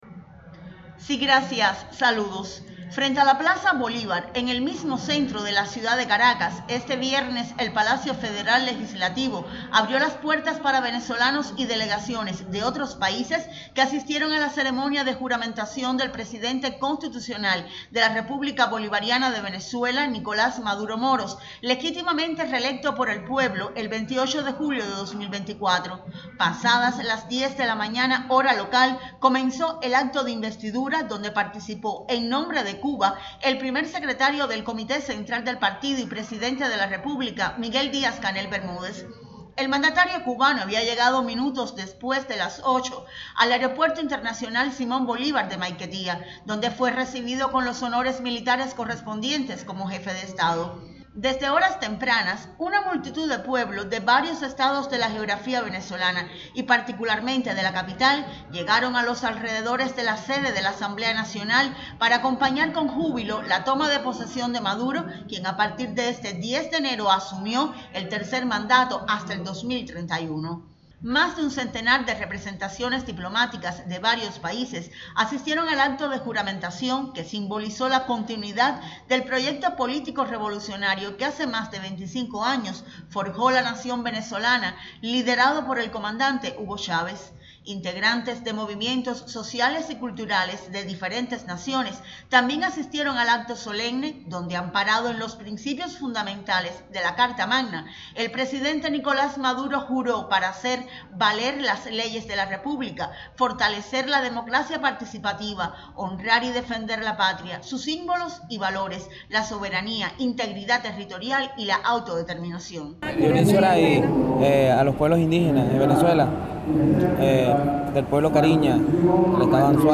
Este viernes en la mañana, desde la sede de la Asamblea Nacional, Nicolás Maduro Moros juramentó como Presidente Constitucional de la República Bolivariana de Venezuela. A la sesión solemne que tuvo lugar en el Salón Elíptico del Palacio Federal Legislativo, asistió el Primer Secretario del Comité Central del Partido Comunista de Cuba y Presidente de la República, Miguel Díaz-Canel Bermúdez.
juramentacion_de_maduro.mp3